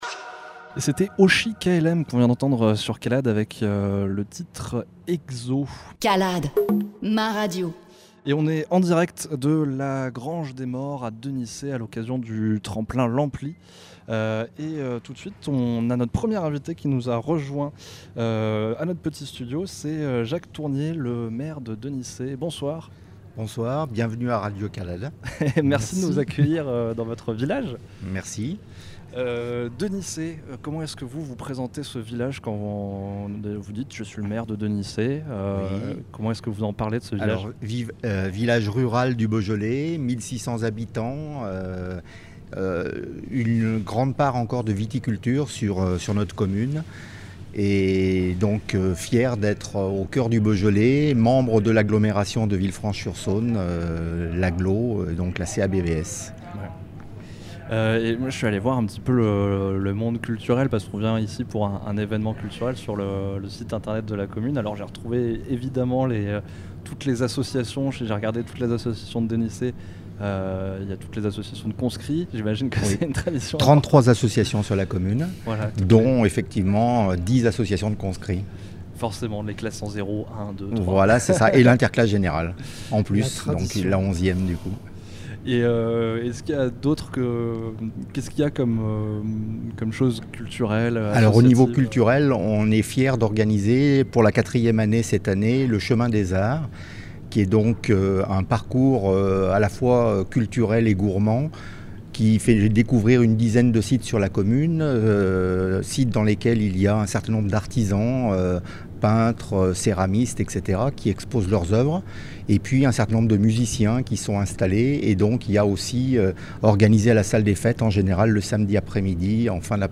Ampli Interview